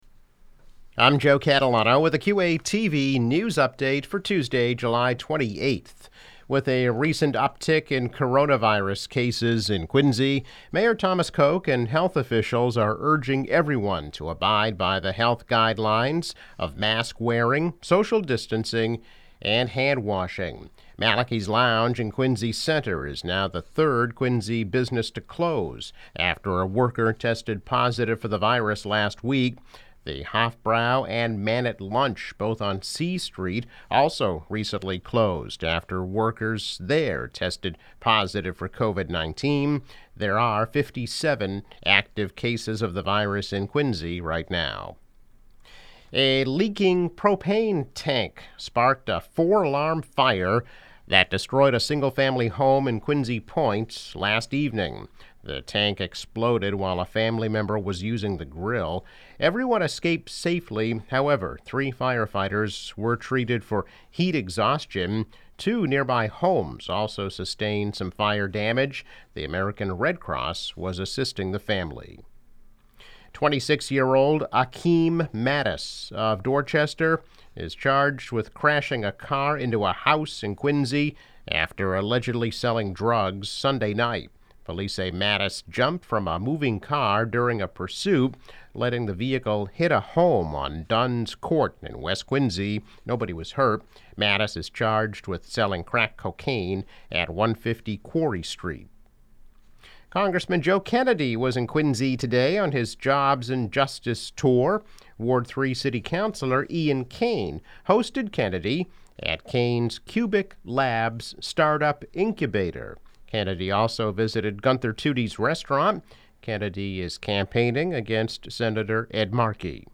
News Update - July 28, 2020